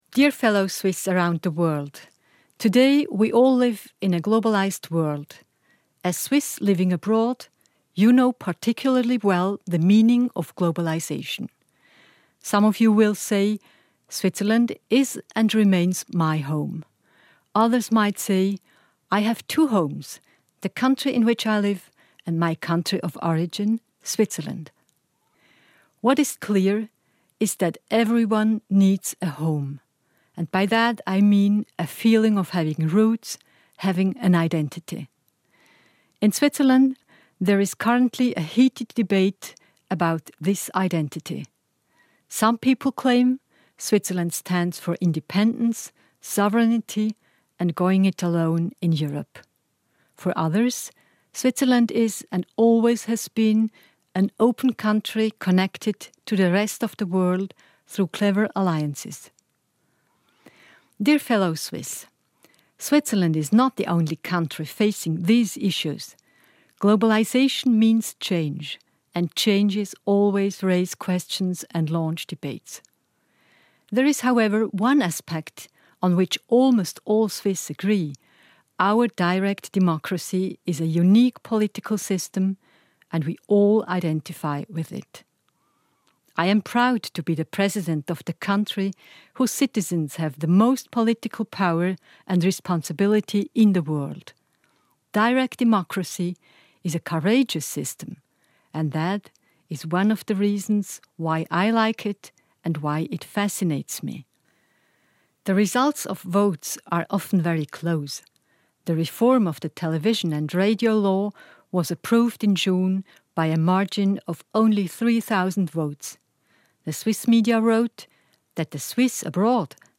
In her speech, President Simonetta Sommaruga wishes all her fellow Swiss abroad a happy August 1 Keystone
1.-August-Rede der Bundespräsidentin